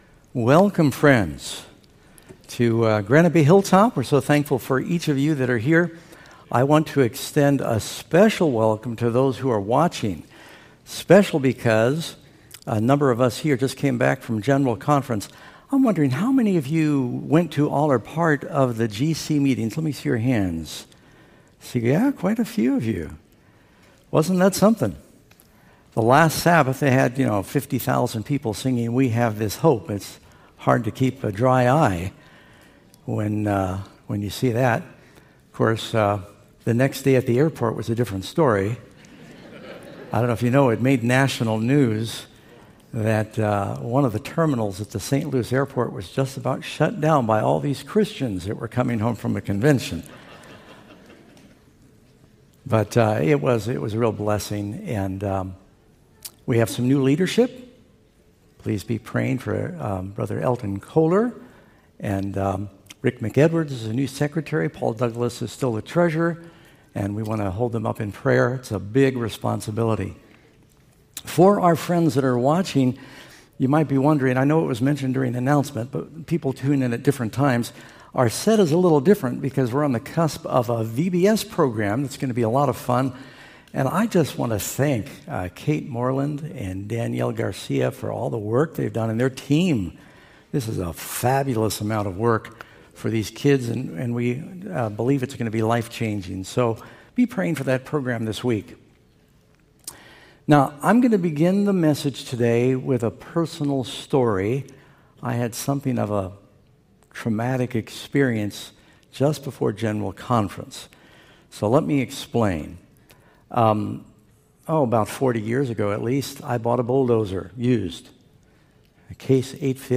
Granite Bay SDA Church